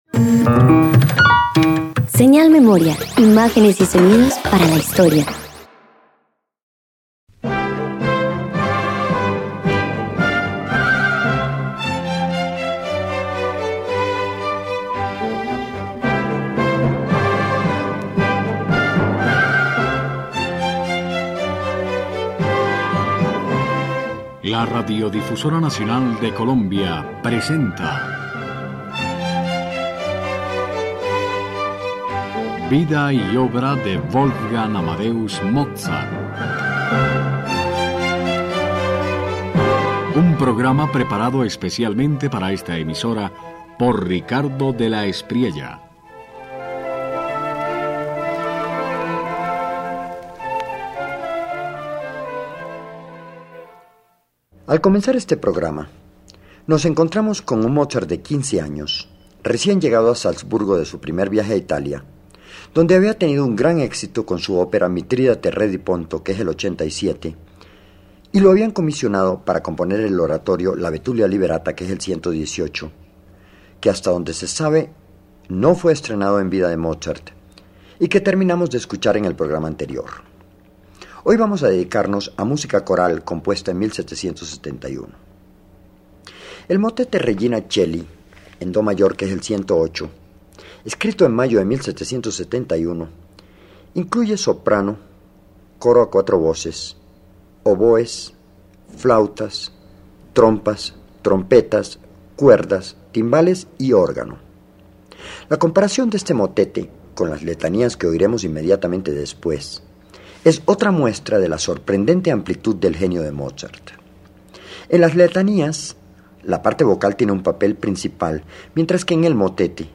El ofertorio para San Juan Bautista introduce un preludio que anuncia madurez: las voces del coro adquieren fisonomía propia en diálogo con un acompañamiento atento. No es júbilo de ópera: es alegría mística, sostenida por la respiración coral.